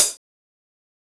HiHat (14).wav